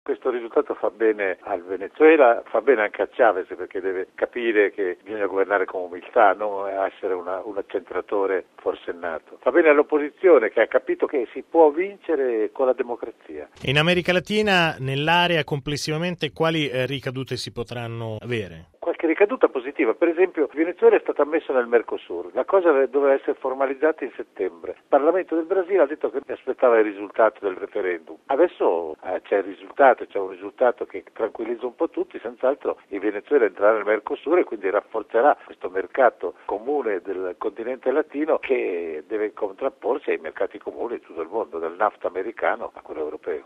esperto di America Latina.